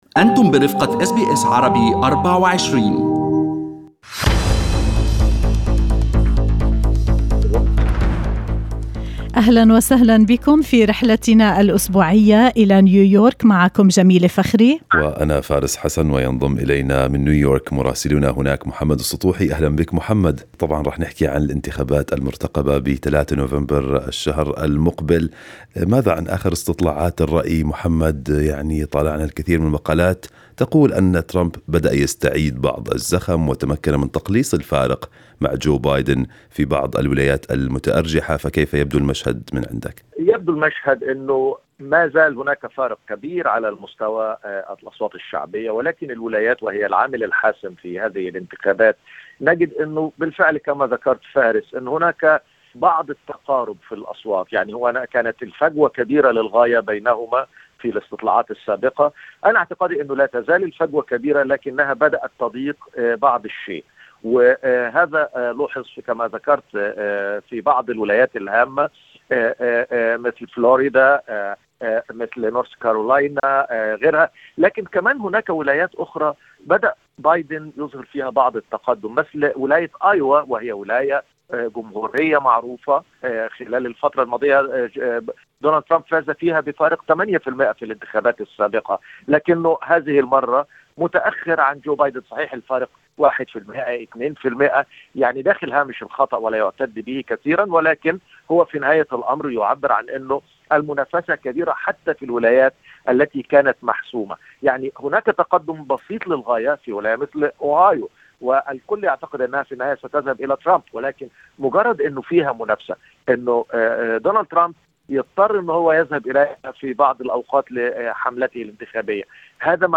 يمكنكم الاستماع إلى تقرير مراسلنا في نيويورك بالضغط على التسجيل الصوتي أعلاه.